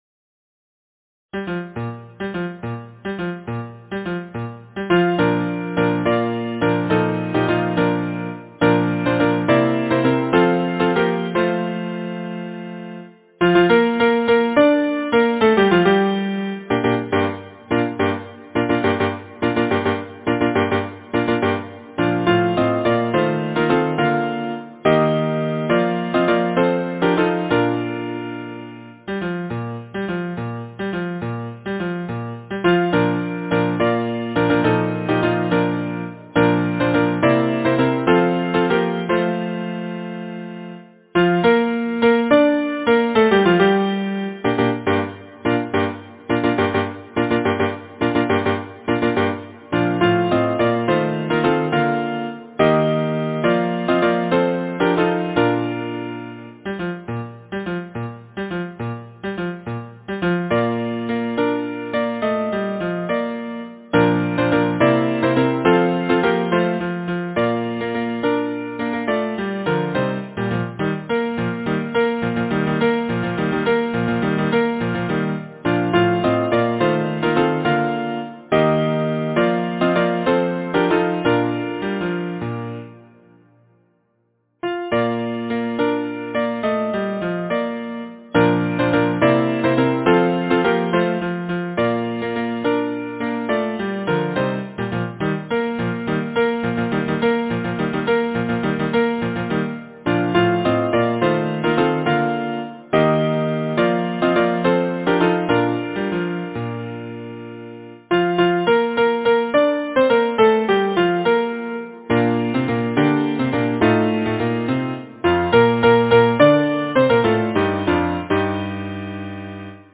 Title: Widdecombe Fair Composer: Anonymous (Traditional) Arranger: Mark Andrews Lyricist: Number of voices: 4vv Voicing: SATB Genre: Secular, Partsong, Folksong
Language: English Instruments: A cappella
First published: 1909 W. H. Gray Co. Description: West of England Folk Song